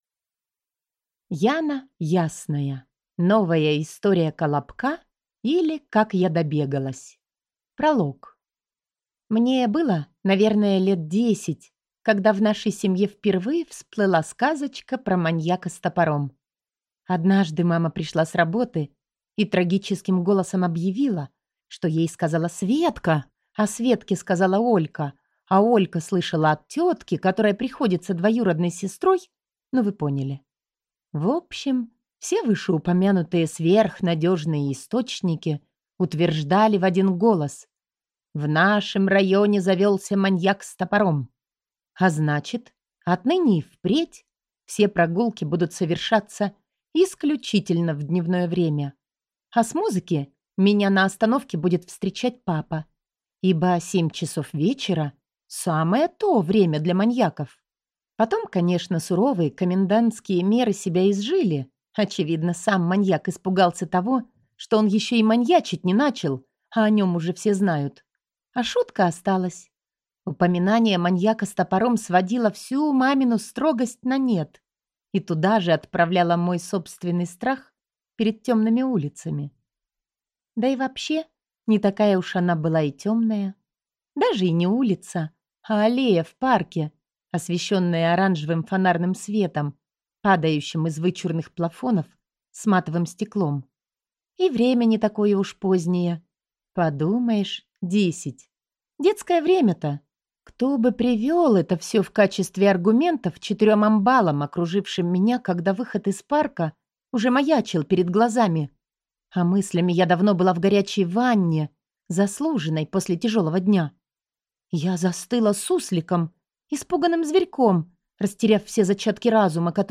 Аудиокнига Новая история колобка, или Как я добегалась | Библиотека аудиокниг